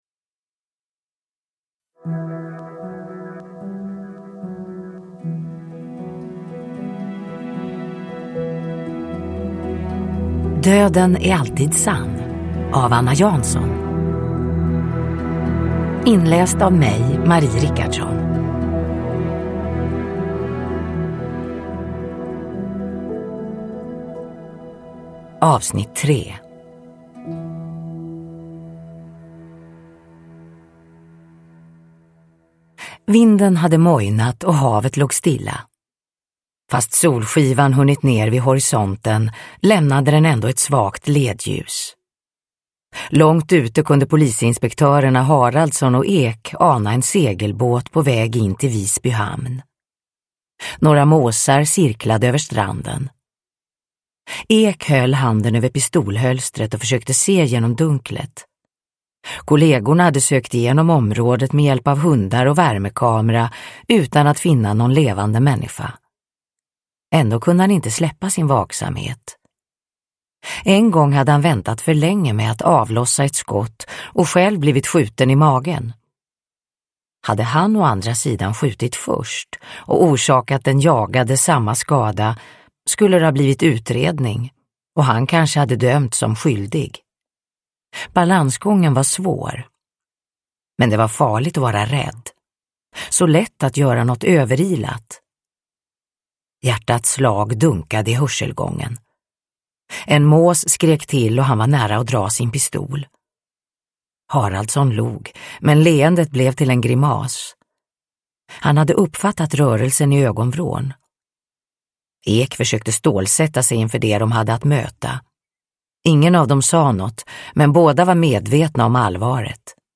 Uppläsare: Marie Richardson